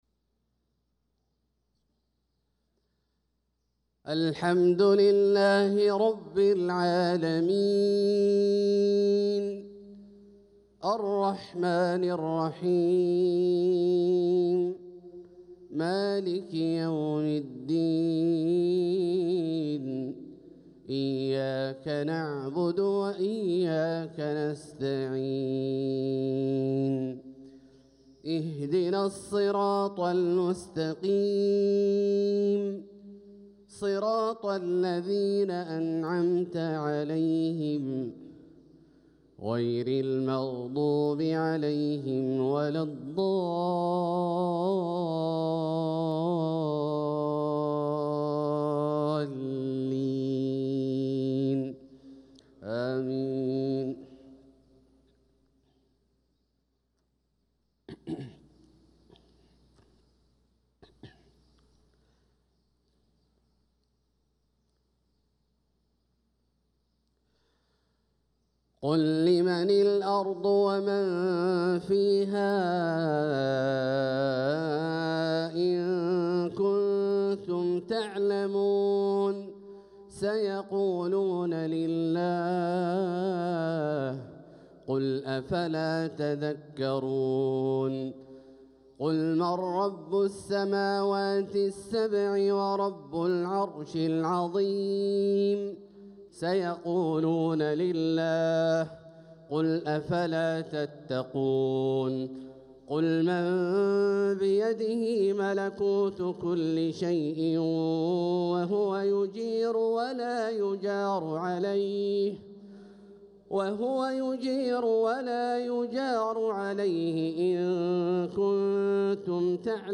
صلاة الفجر للقارئ عبدالله الجهني 21 ربيع الأول 1446 هـ